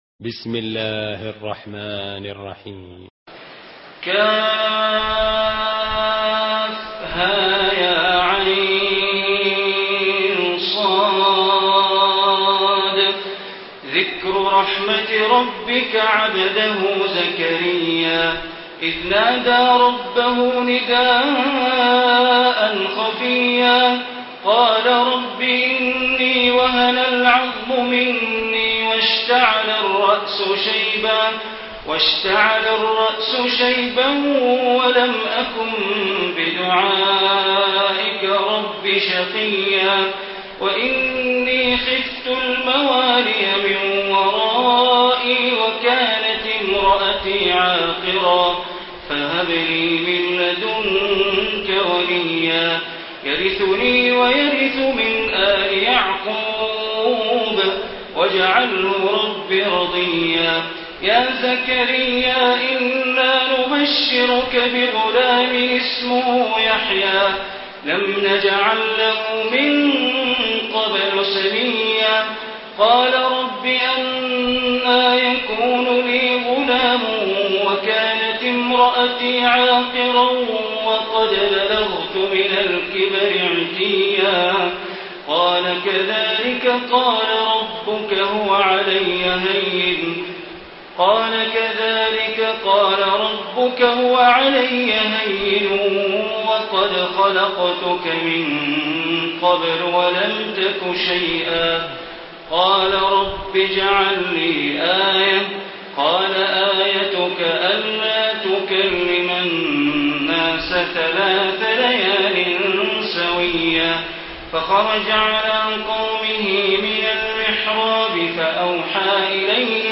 Surah Maryam Recitation by Sheikh Bandar Baleela
Surah Maryam, listen online mp3 tilawat / recitation in Arabic in the beautiful voice of Imam e Kaaba Sheikh Bandar Baleela.